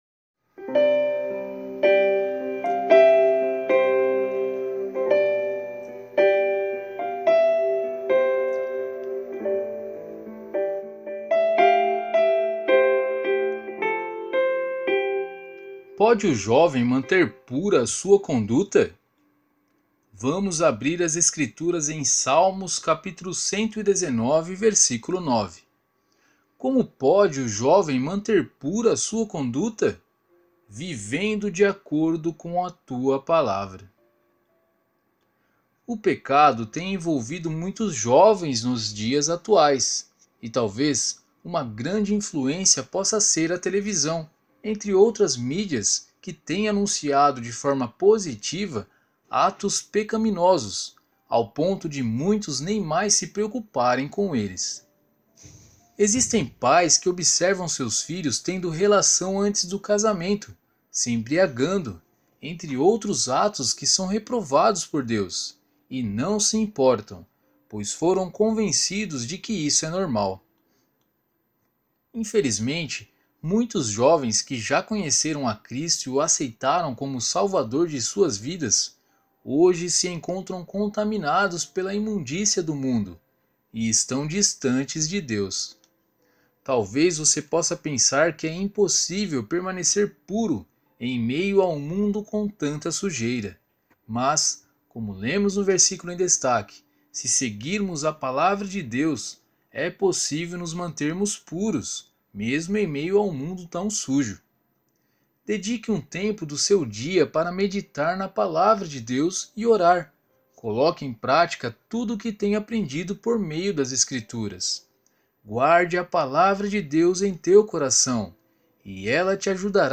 Você também pode ouvir a narração do Alimento Diário!